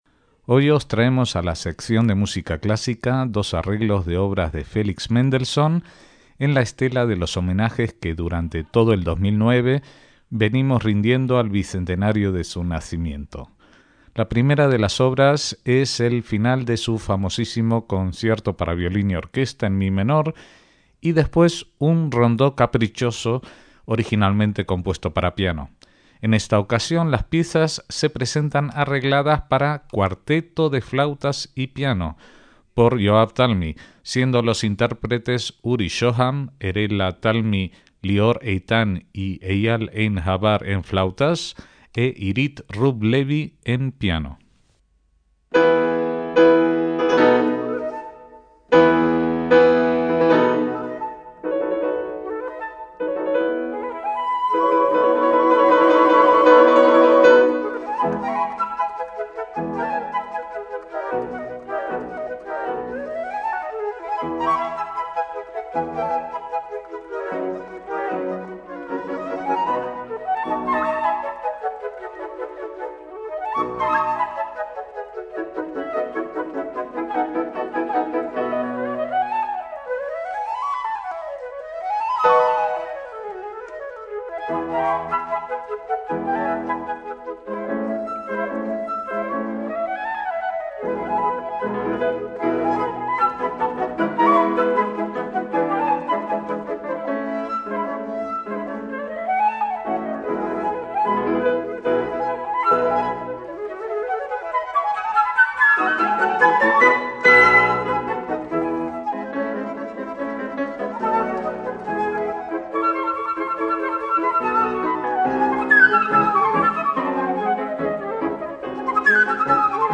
MÚSICA CLÁSICA
el cuarteto de flautas